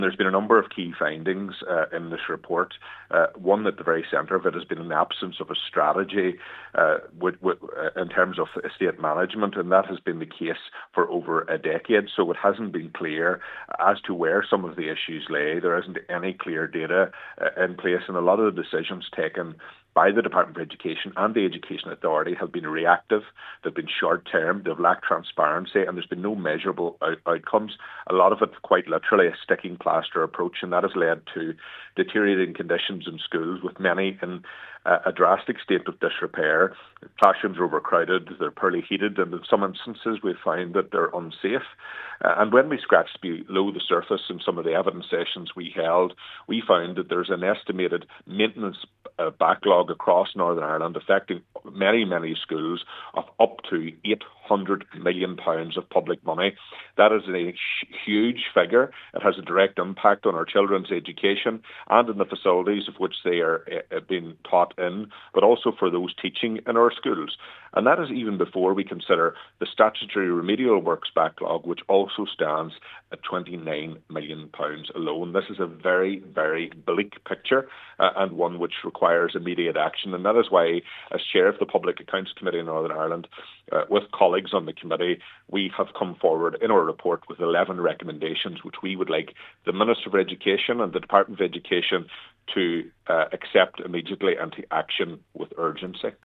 West Tyrone MLA and Chair of the Public Accounts Committee Daniel McCrossan says many schools in the North are in a state of disrepair and the current situation is “unsafe”: